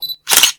camera.ogg